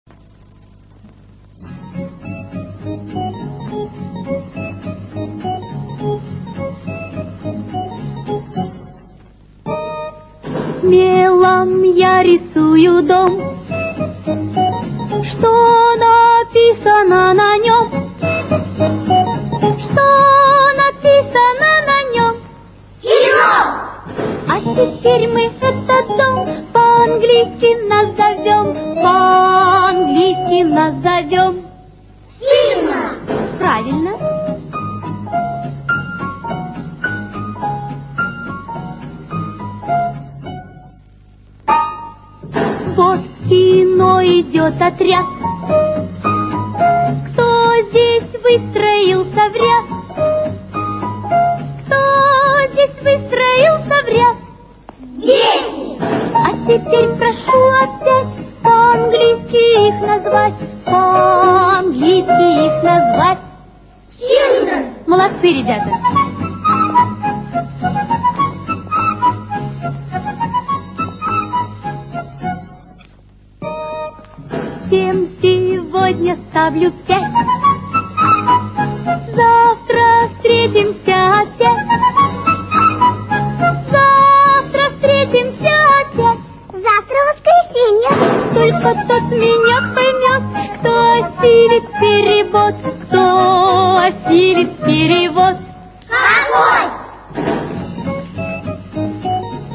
Музыка к кинофильмам